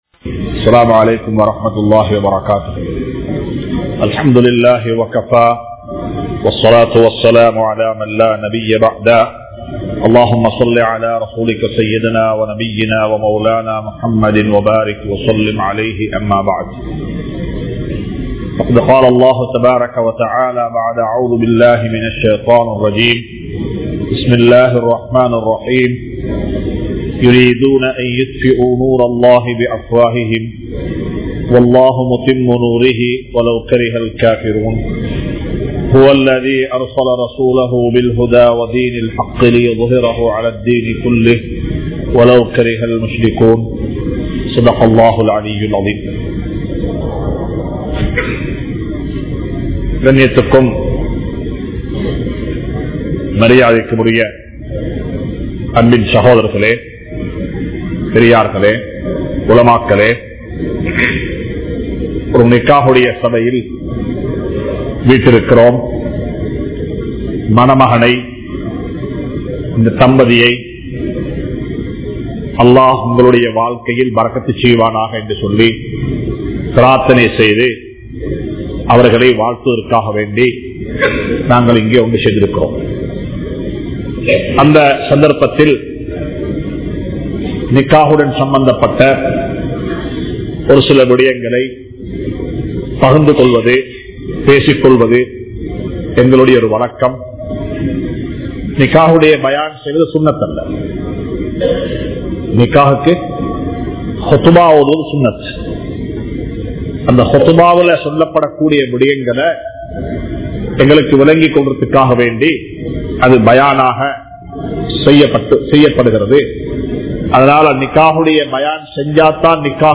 Oru Thanthaien Kadamaihal (ஒரு தந்தையின் கடமைகள்) | Audio Bayans | All Ceylon Muslim Youth Community | Addalaichenai